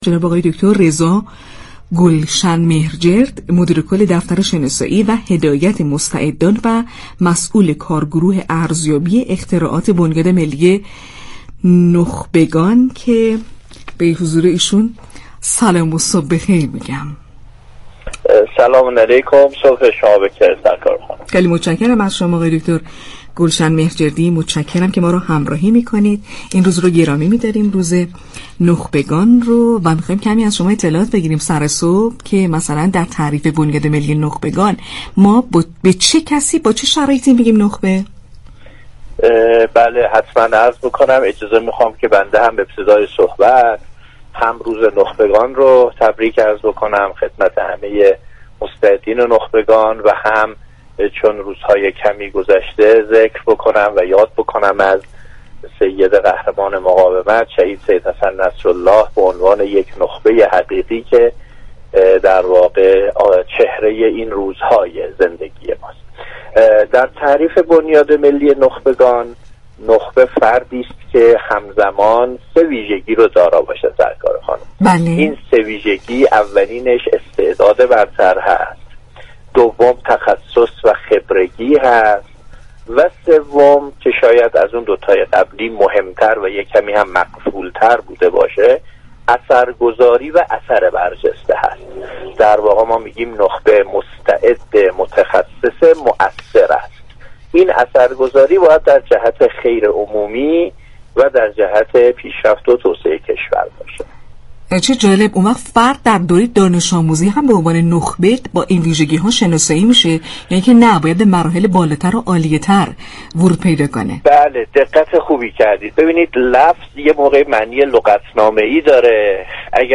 گفت و گو با ویژه برنامه «صبح تهران، داغ بیروت»